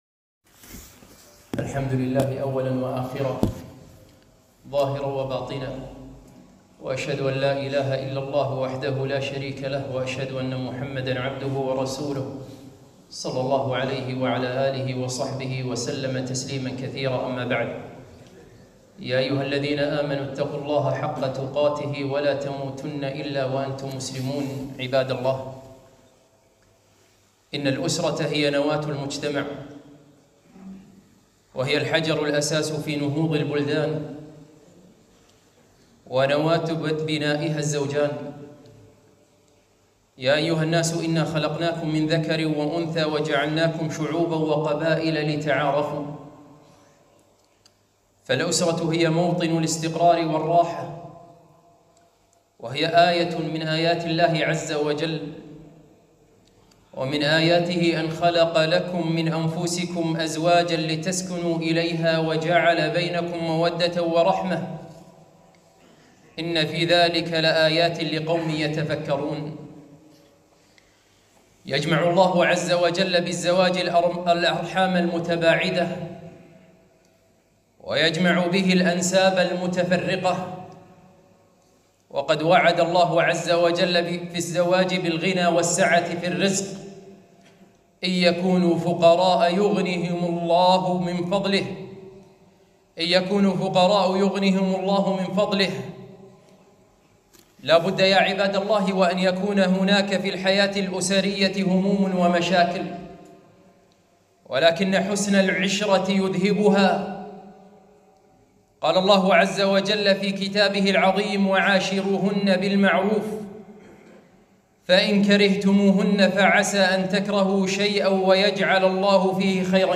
خطبة - رسالة إلى زوجين